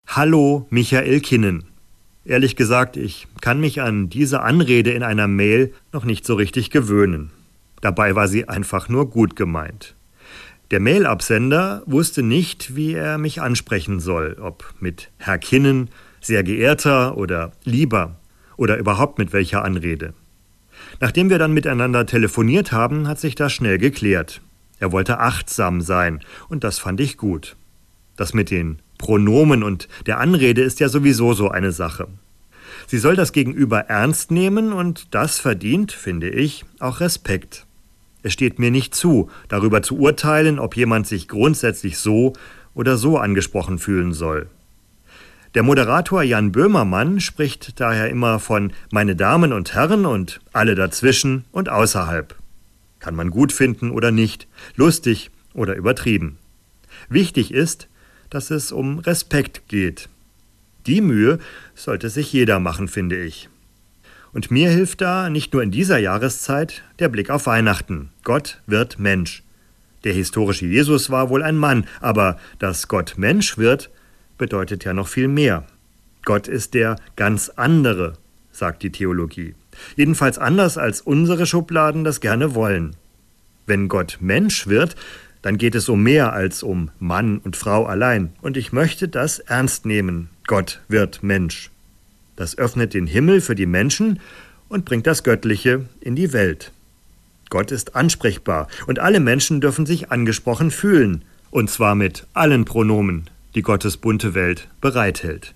Das christliche Wort zum Alltag